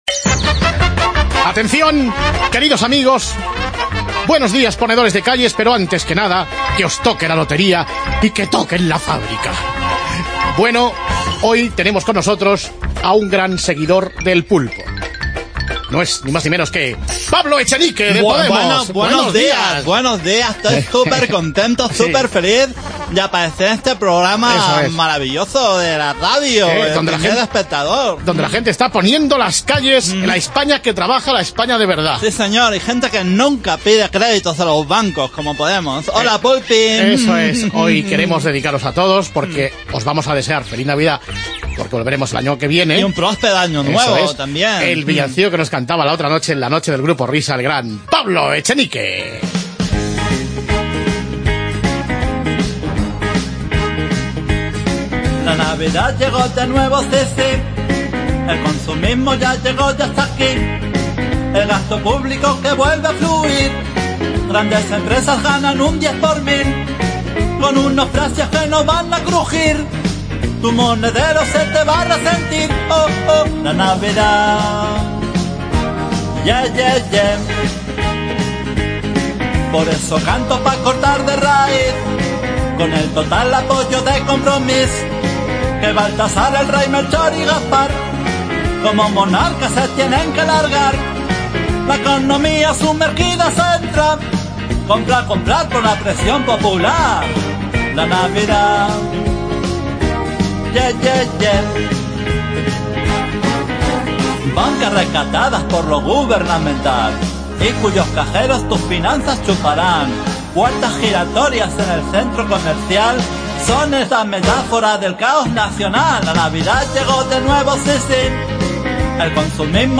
El toque de humor que ofrecen